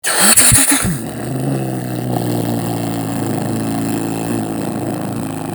Startup - Mitsubishi Lancer Evolution Bouton sonore